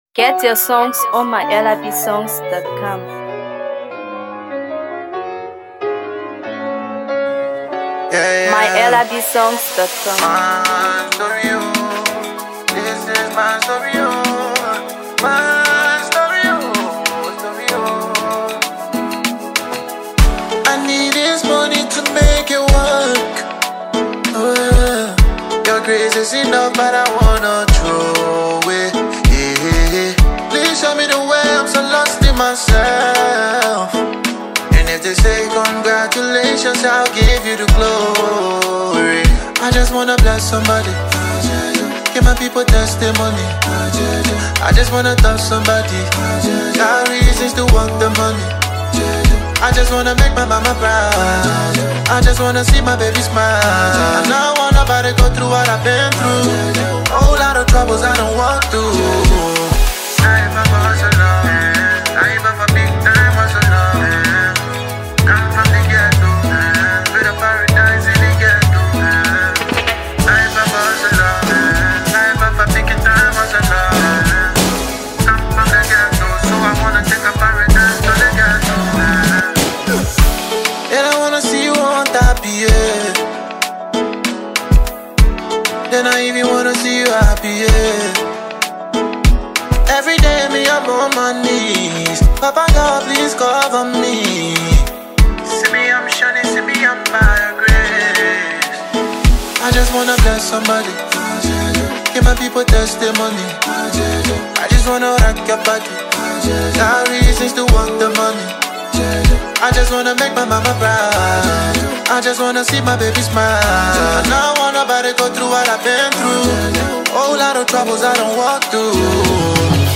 Afro PopMusic
Afro Beats
engaging melody